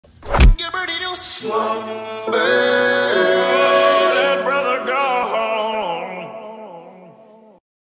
Funny Goofy Ahh Sound Button - Bouton d'effet sonore